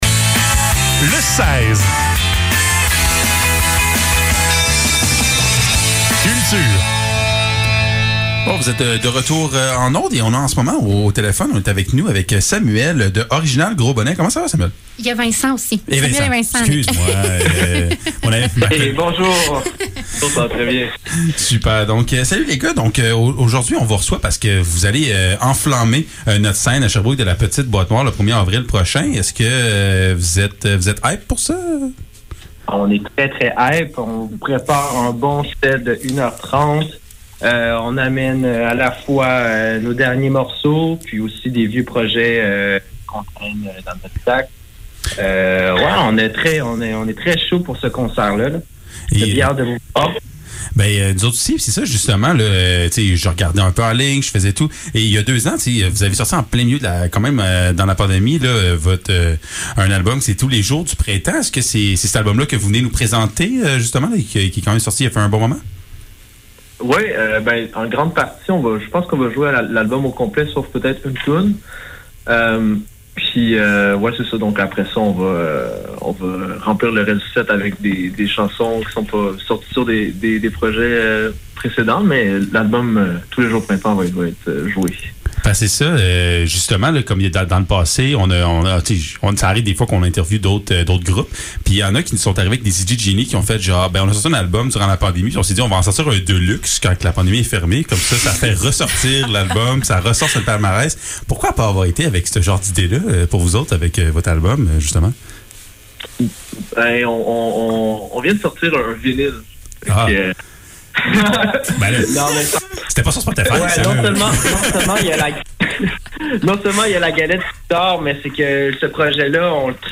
Le seize - Entrevue avec Original Gros Bonnet - 30 mars 2022